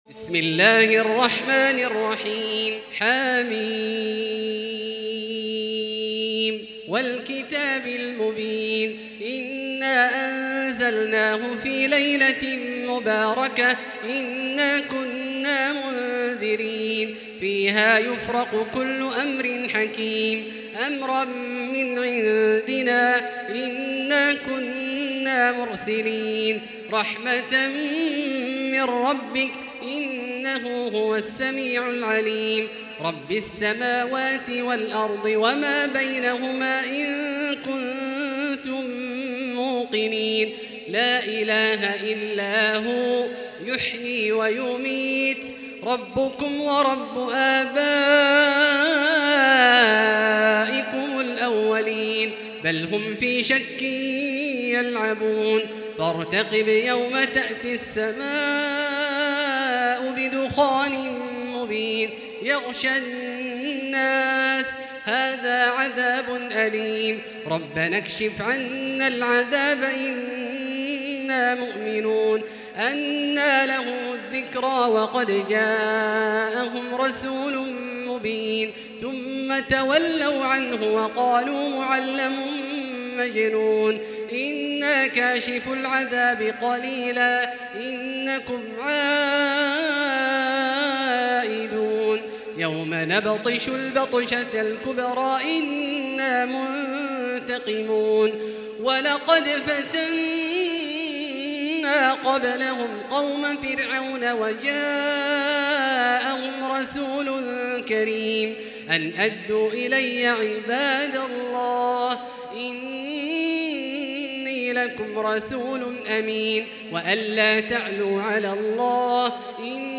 المكان: المسجد الحرام الشيخ: فضيلة الشيخ عبدالله الجهني فضيلة الشيخ عبدالله الجهني الدخان